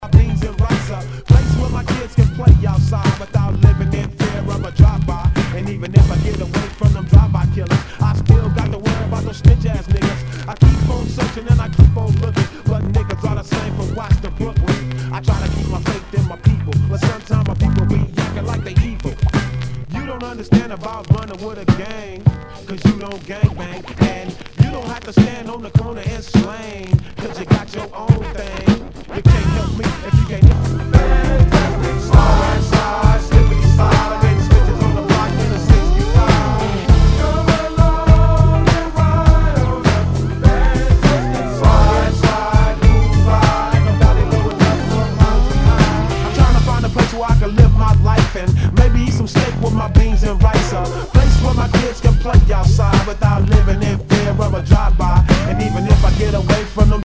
HIPHOP/R&B
全体に大きくチリノイズが入ります